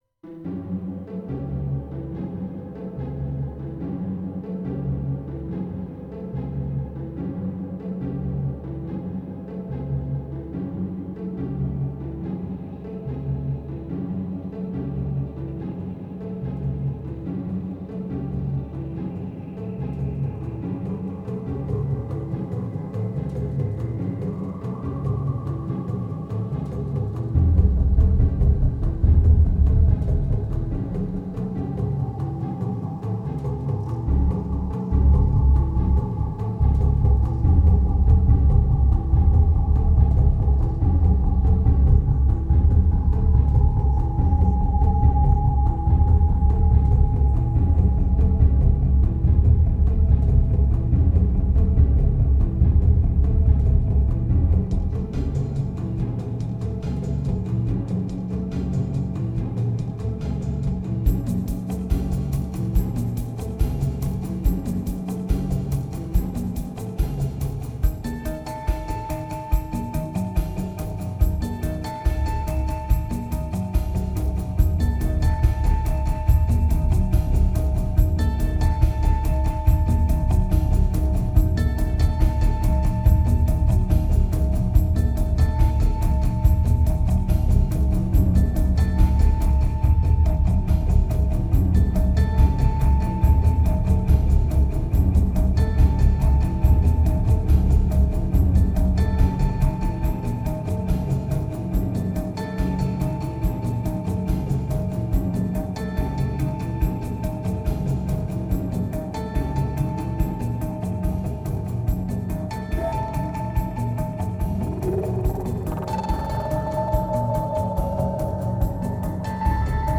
winter moods recorded in Paris
2000📈 - -2%🤔 - 143BPM🔊 - 2010-12-05📅 - -174🌟